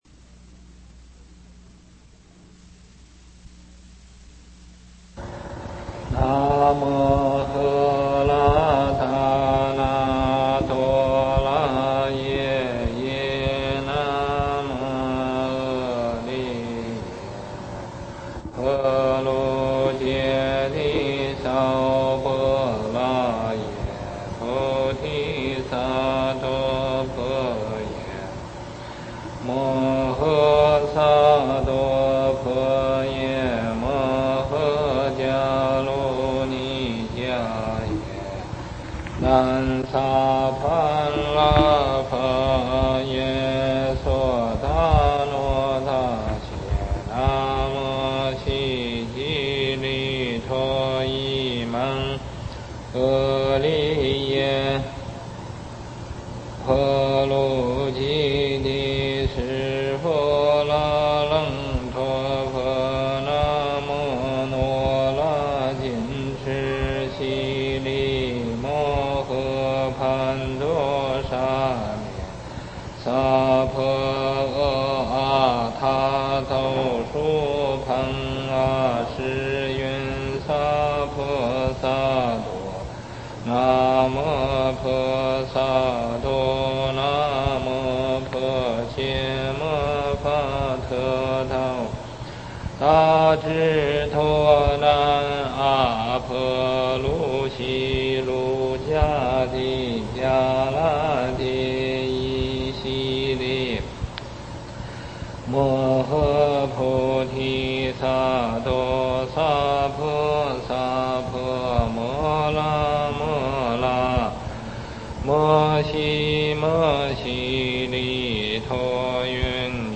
全世界最流行的佛教梵唱曲，旋律优美，功德殊胜，适合日常持诵。佛光山梵呗赞颂团演唱版本。